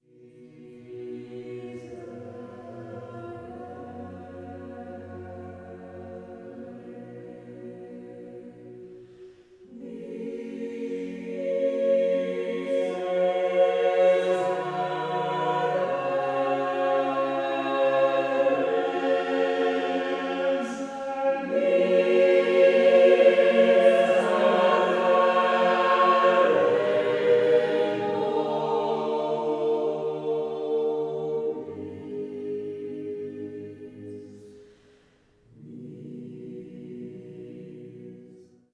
Aylesbury Consort of Voices, Renaissance Concert, St Joseph’s Aylesbury, June 2022